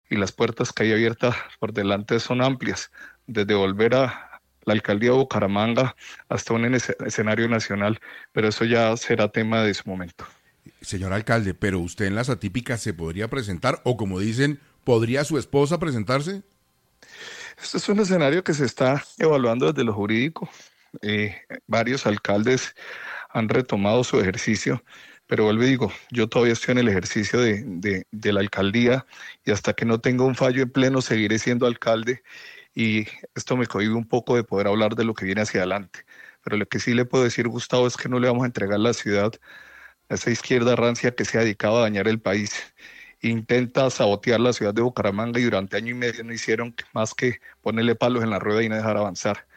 Horas después de que el Consejo de Estado anulará su elección por doble militancia, el alcalde de Bucaramanga, Jaime Andrés Beltrán Martínez dijo a Caracol Radio que explora otros escenarios, incluso los de una eventual participación en las elecciones atípicas a las que se convocarán antes de terminar el año.
En un reportaje con la mesa de trabajo de 6:00 A.M., el alcalde aseguró que “las puertas que se han abierto son amplias” tras conocerse el fallo que lo aparta del cargo.